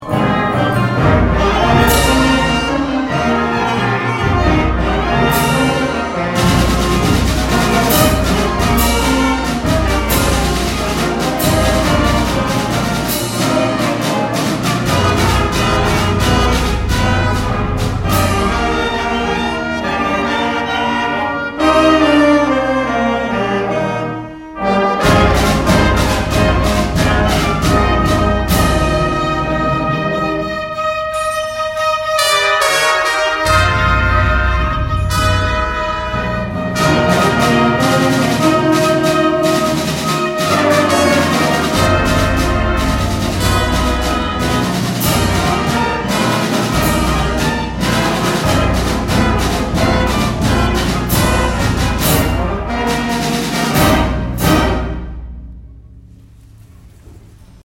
第１４回南那須地区音楽祭にブラスバンド部が出場しました。
迫力ある演奏をお聴きください。